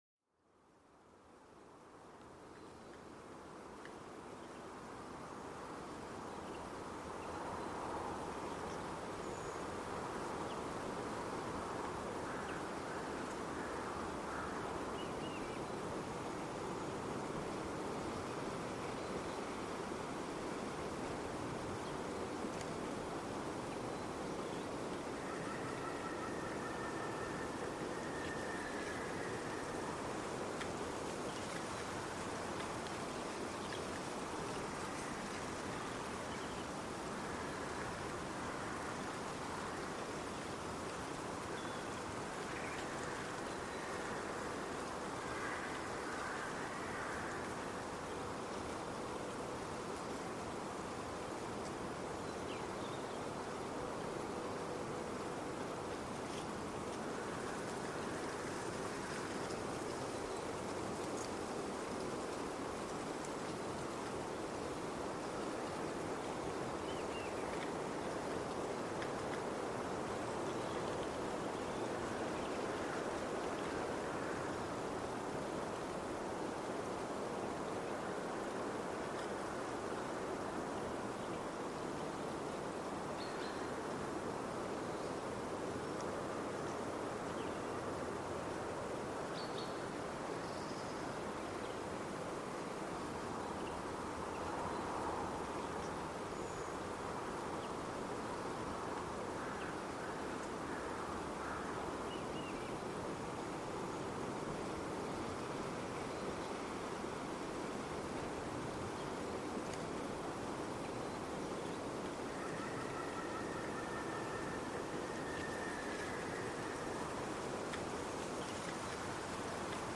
Sumérgete en la atmósfera tranquilizadora del viento que susurra a través de los árboles del bosque. Déjate envolver por la suave sinfonía de la naturaleza, fortaleciendo tu conexión con el entorno.